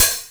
HH OPEN12.wav